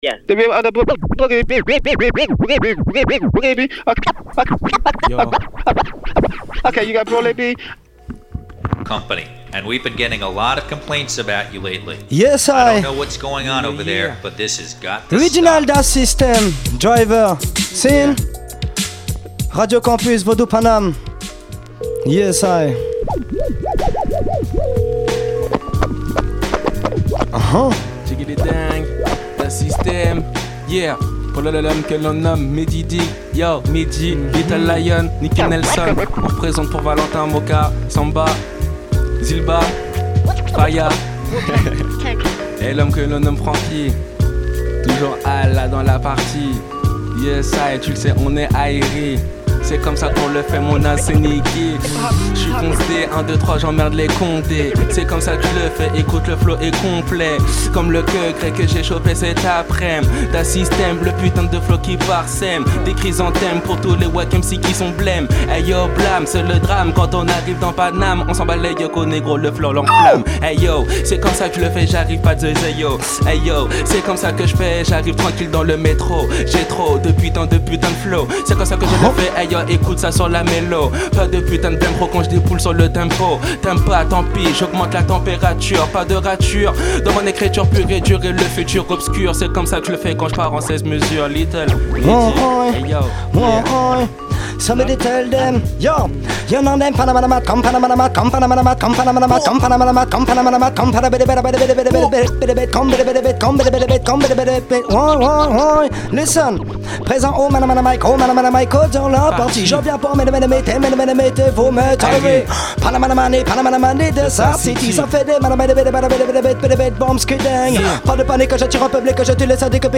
Session Freestyle